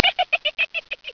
snd_11482_GIGGLE.wav